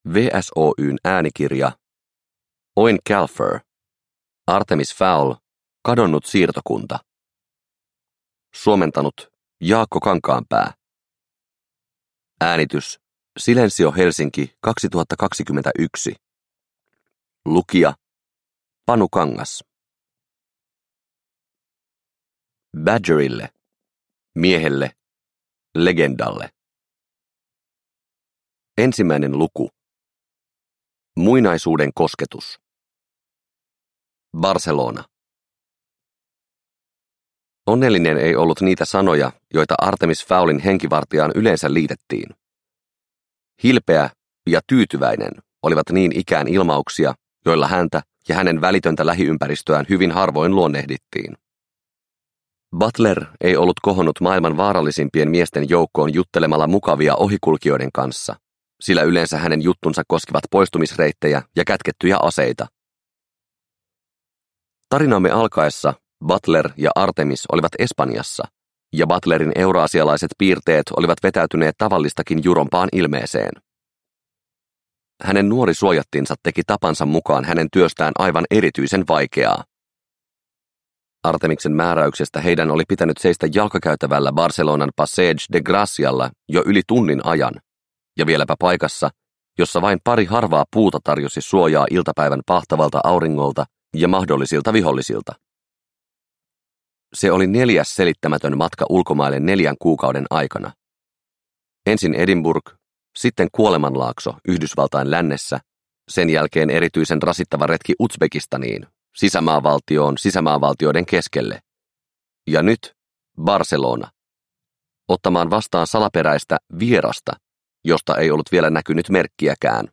Artemis Fowl: Kadonnut siirtokunta – Ljudbok – Laddas ner